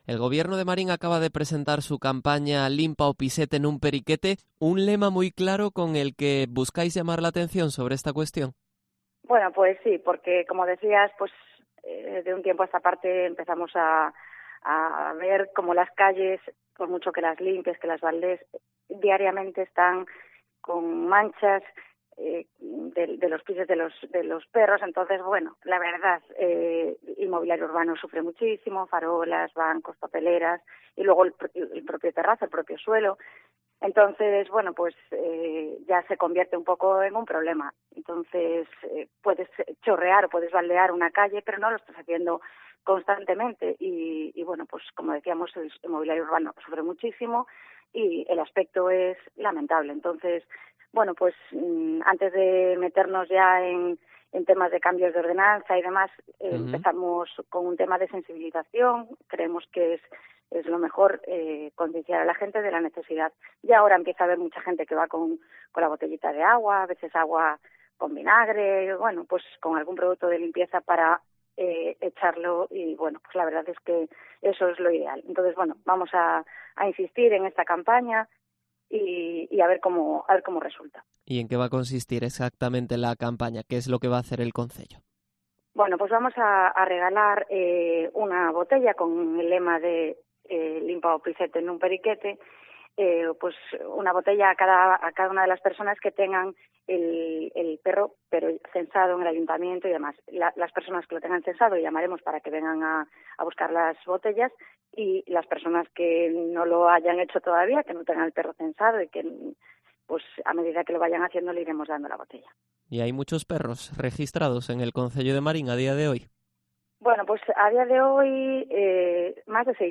Entrevista a Marián Sanmartín, concejala de Medio Ambiente de Marín